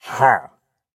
Minecraft Version Minecraft Version snapshot Latest Release | Latest Snapshot snapshot / assets / minecraft / sounds / mob / pillager / idle3.ogg Compare With Compare With Latest Release | Latest Snapshot